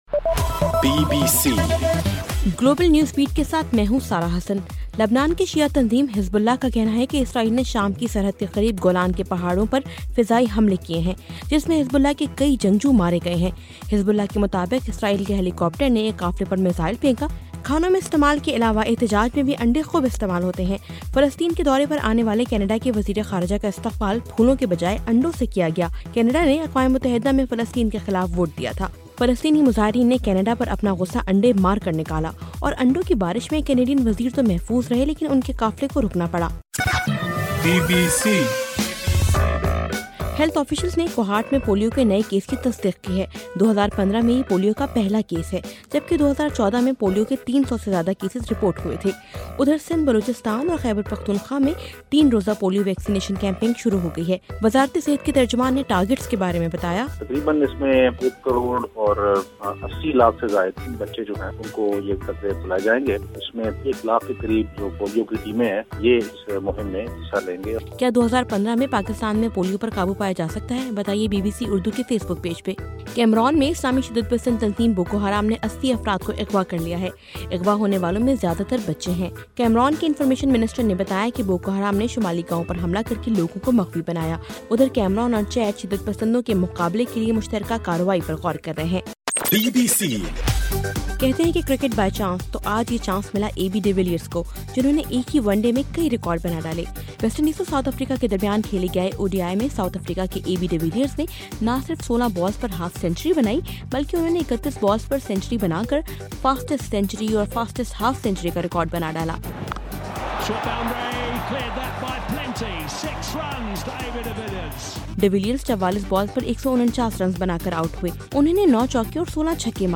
جنوری 19: صبح 1 بجے کا گلوبل نیوز بیٹ بُلیٹن